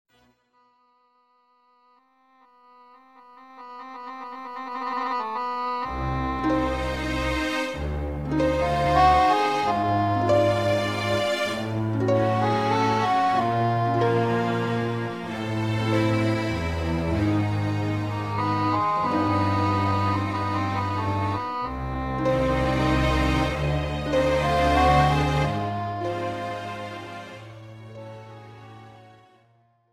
This is an instrumental backing track cover.